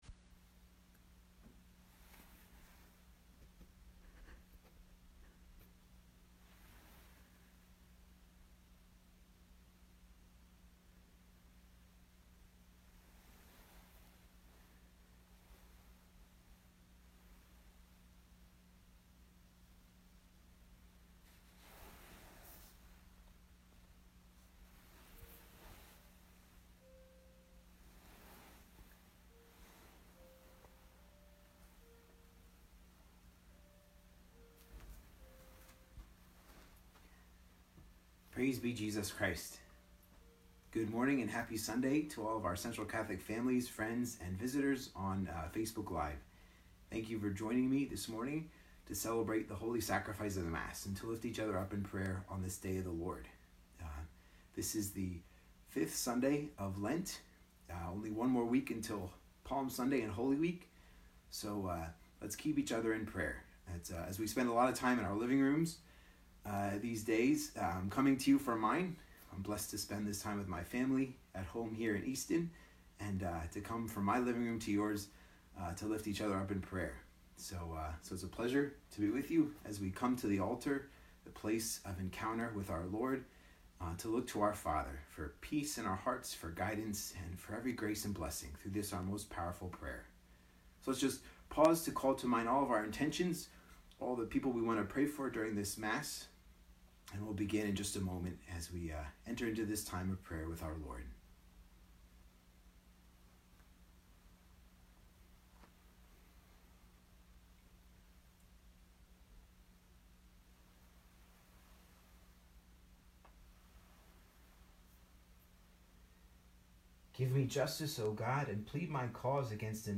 Sermon or written equivalent